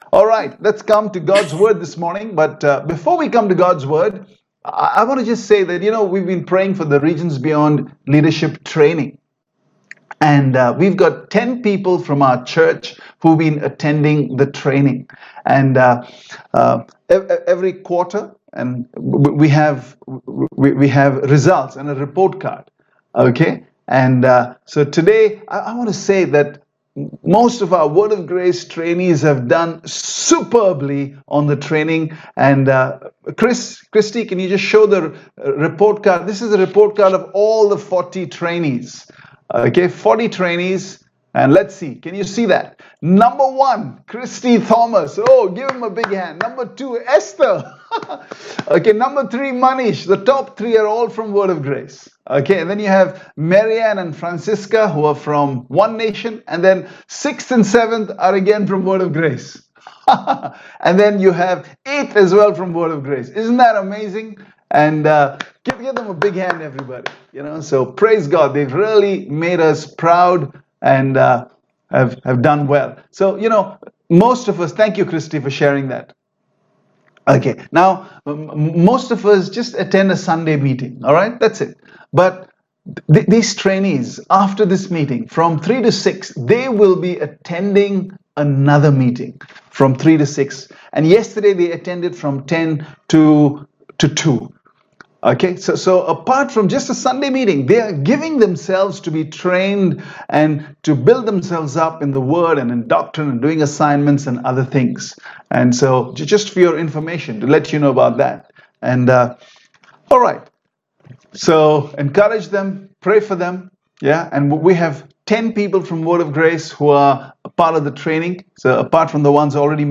Zoom Worship